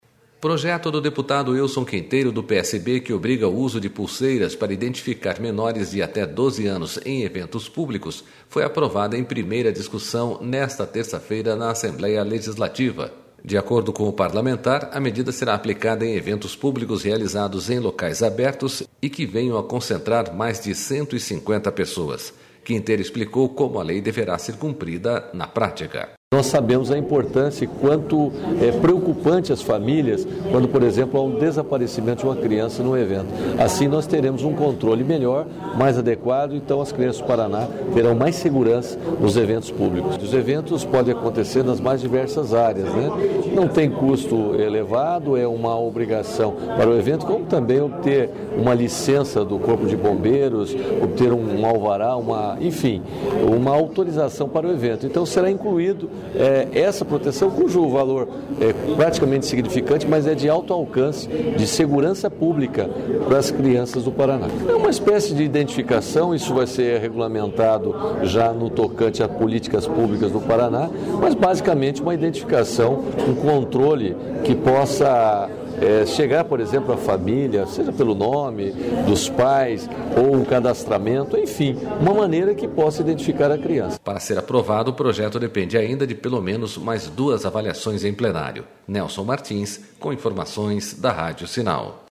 Quinteiro explicou como a lei deverá ser cumprida na prática.//
SONORA QUINTEIRO